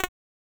Abstract Click (3).wav